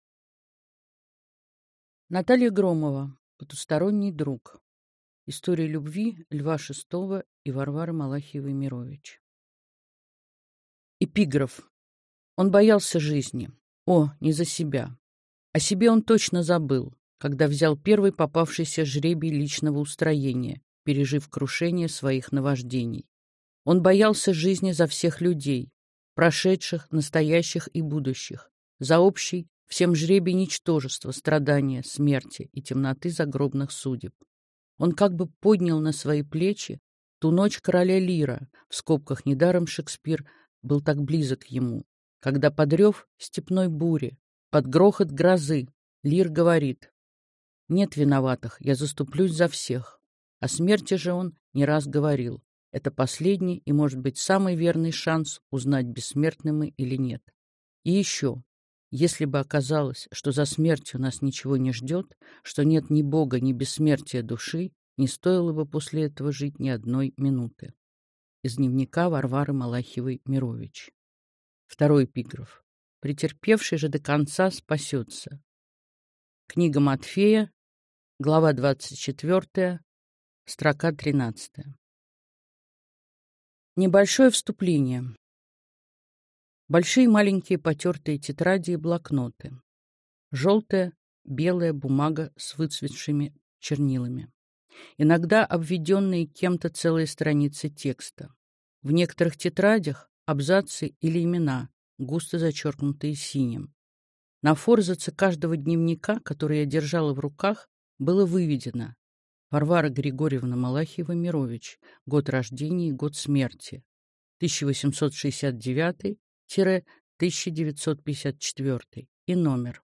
Аудиокнига Потусторонний друг. История любви Льва Шестова и Варвары Малахиевой-Мирович в письмах и документах | Библиотека аудиокниг